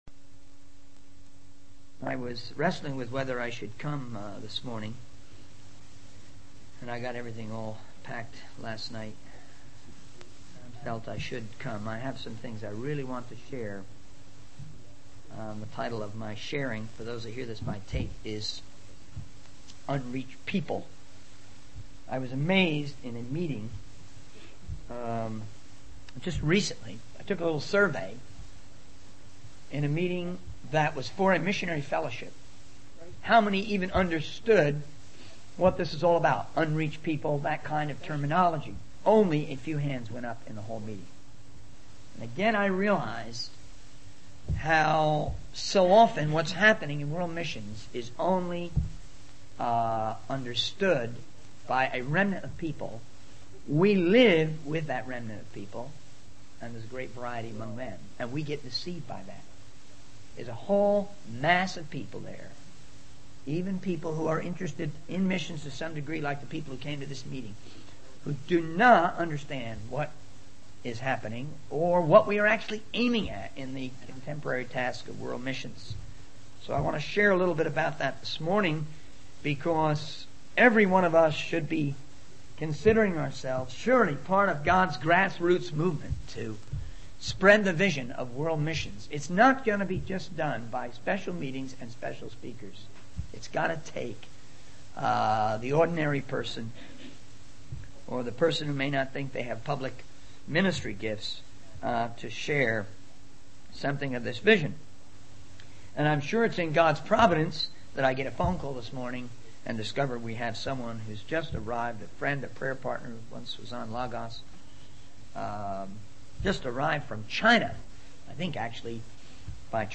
In this sermon, the speaker emphasizes the importance of understanding and spreading the vision of world missions.